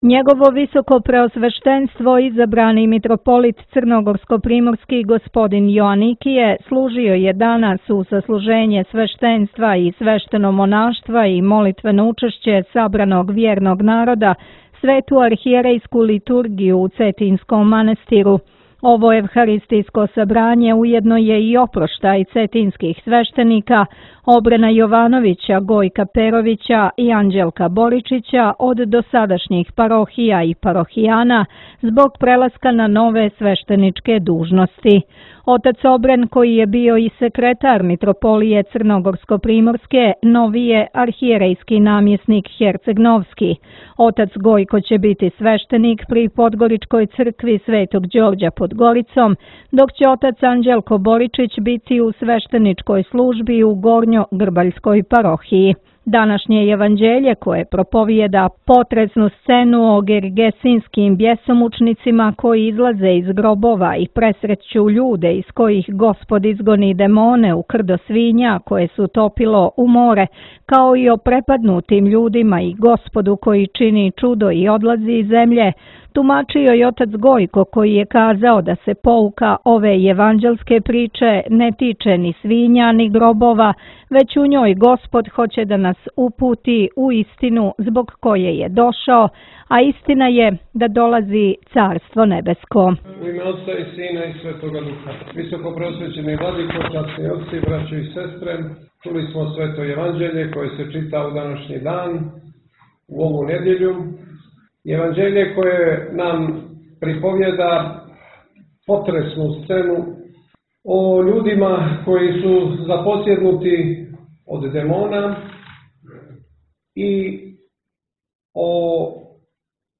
Његово високопреосвештенство изабрани Митрополит црногорско-приморски г. Јоаникије служио је у недељу пету по Духовима, 25. јула, на празник иконе Богородице Тројеручице, са свештенством и свештеномонаштвом Свету архијерејску литургију у Цетињском манастиру.